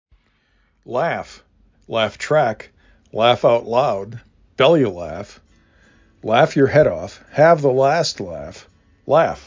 l a f